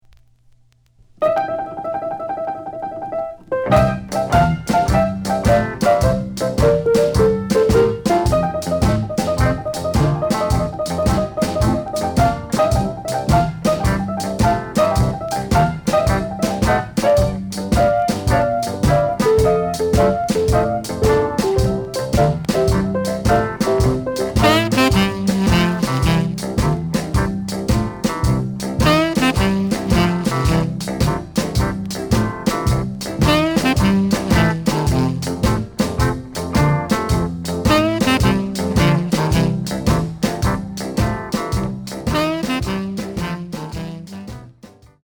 The audio sample is recorded from the actual item.
●Genre: Rhythm And Blues / Rock 'n' Roll
Some click noise on A side due to scratches.